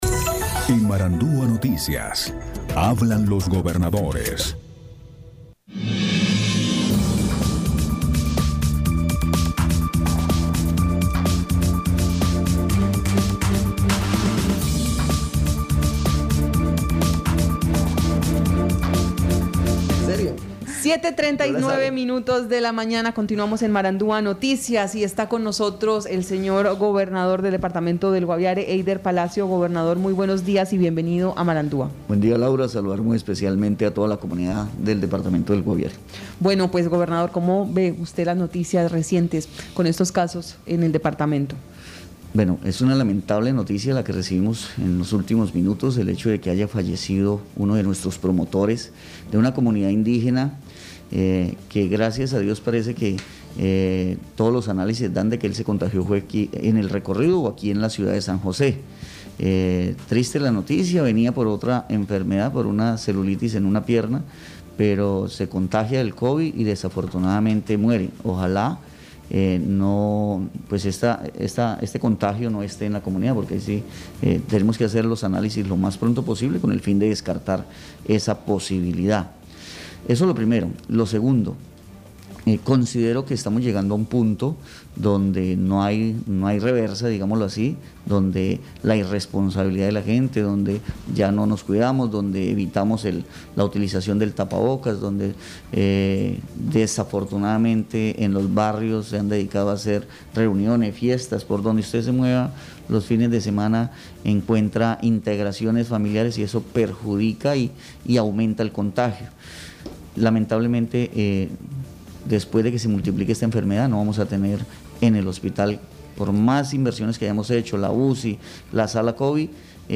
Escuche a Heydeer Palacio, gobernador del Guaviare.